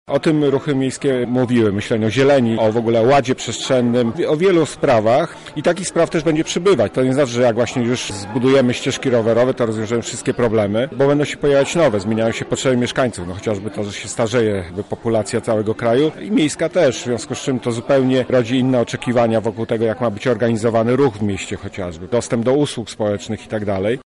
Ruchy miejskie zawsze były punktem oporu wobec proponowanej polityki – komentuje socjolog Edwin Bendyk: